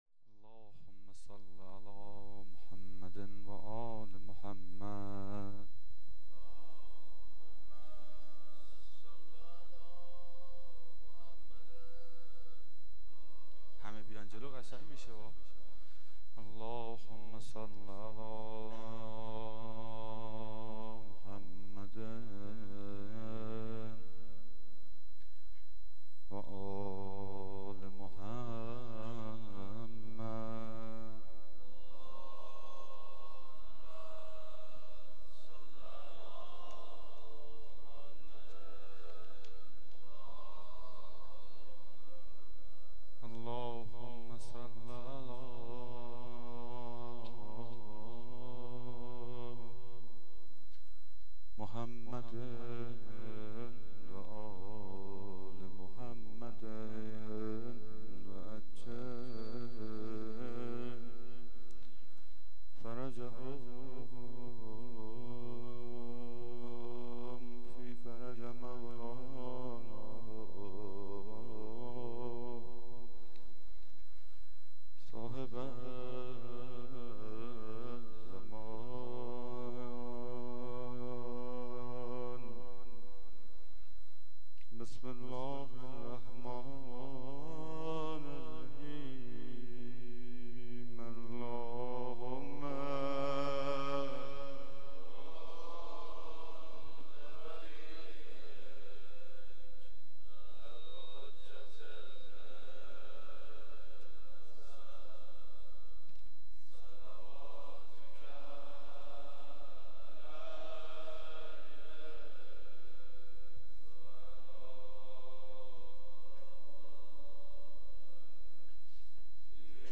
روضه و مناجات